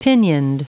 Prononciation du mot pinioned en anglais (fichier audio)
Prononciation du mot : pinioned